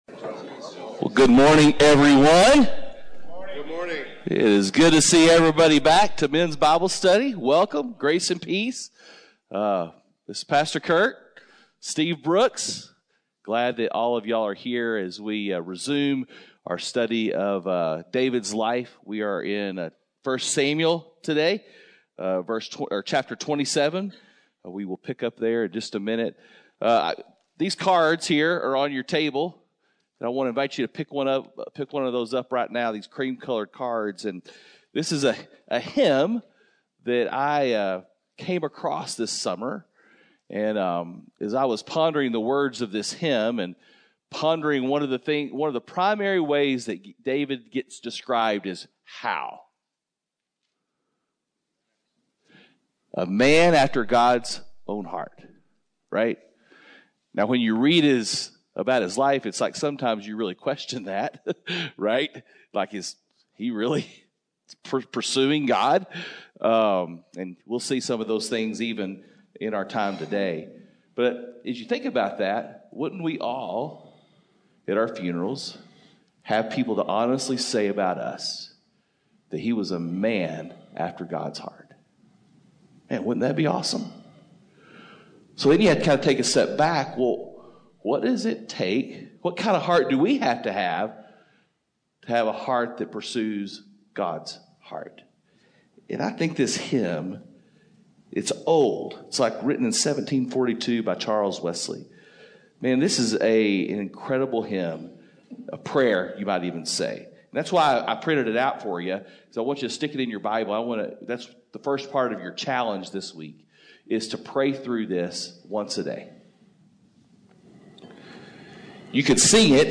Men’s Breakfast Bible Study 8/10/21